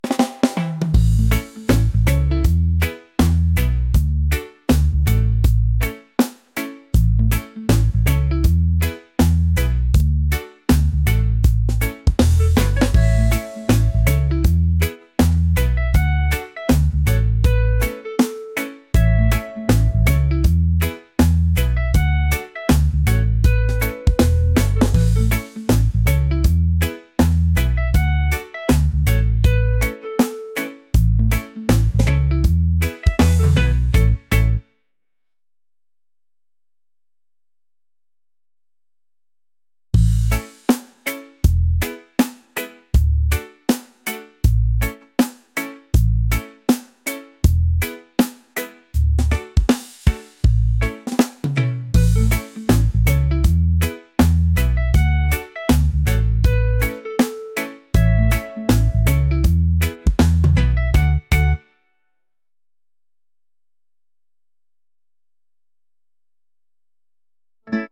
laid-back | upbeat | reggae